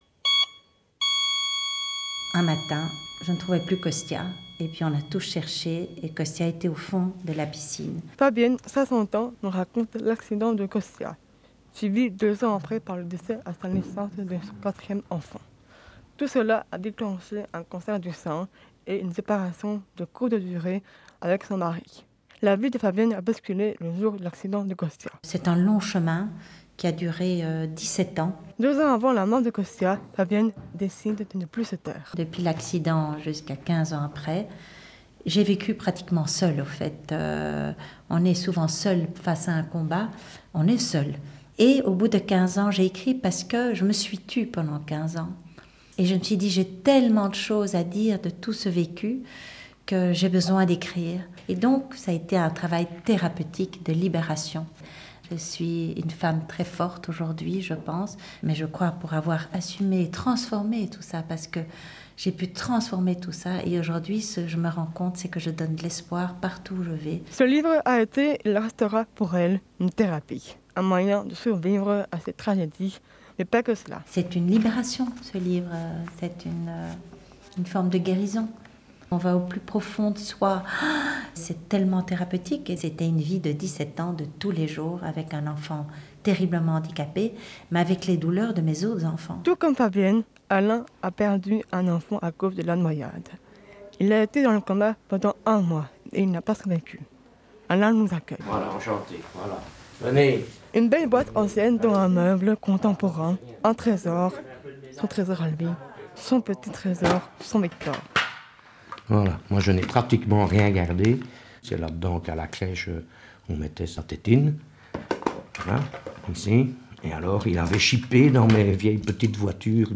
Reportage radio